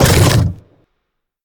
biter-roar-big-1.ogg